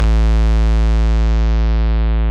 VEC1 Bass Long 06 A.wav